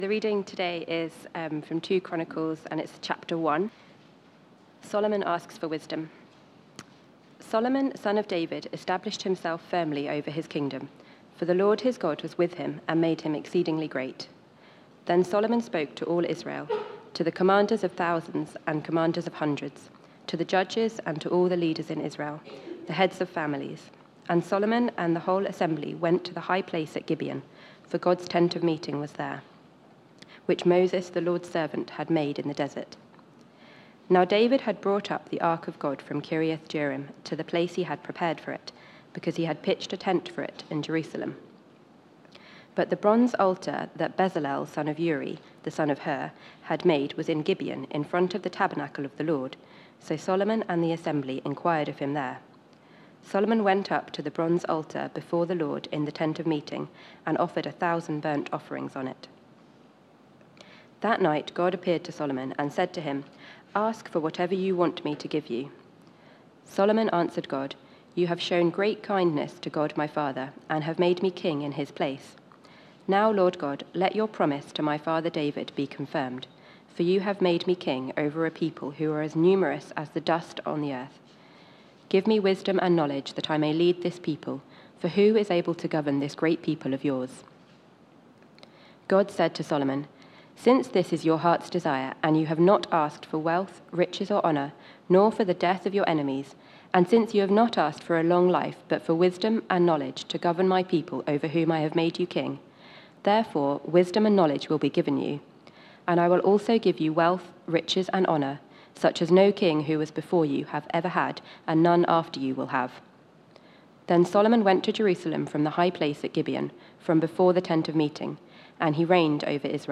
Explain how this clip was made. Media for Sunday Service on Sun 09th Jun 2024 10:00